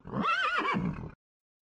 Pferde Wiehern klingelton kostenlos
Kategorien: Tierstimmen
pferde-wiehern.mp3